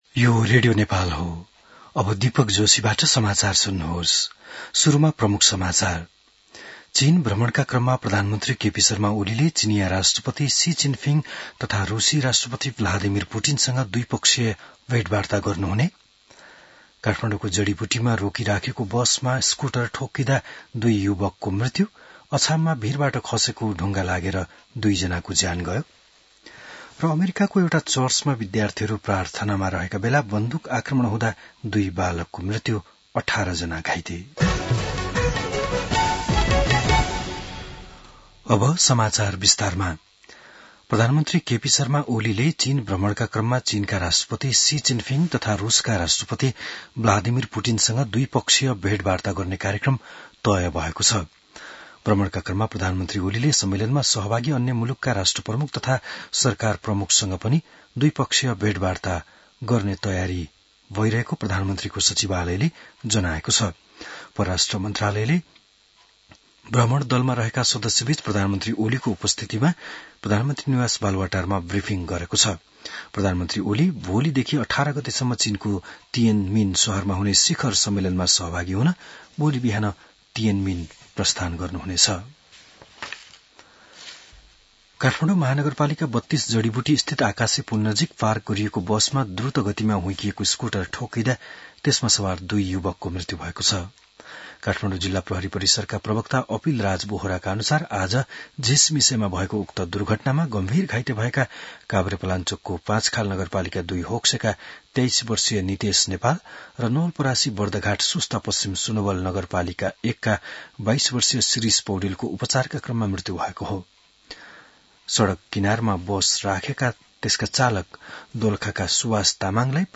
बिहान ९ बजेको नेपाली समाचार : १३ भदौ , २०८२